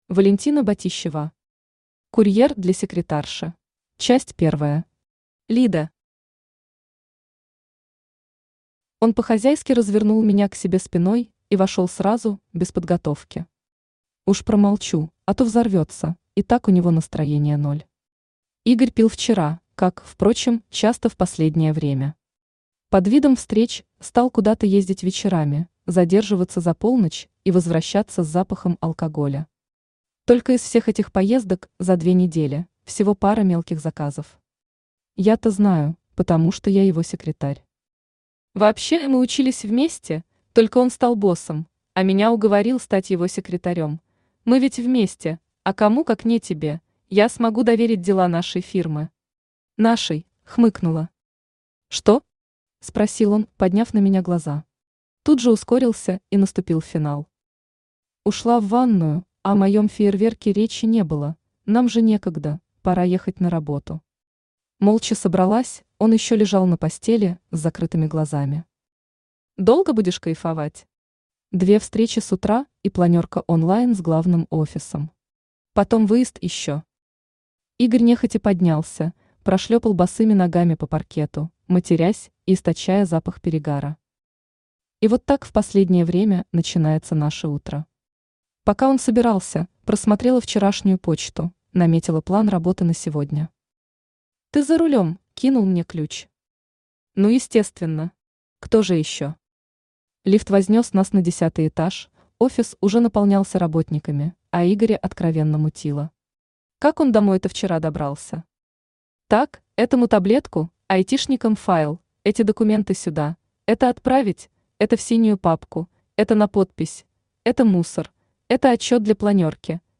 Aудиокнига Курьер для секретарши Автор Валентина Батищева Читает аудиокнигу Авточтец ЛитРес.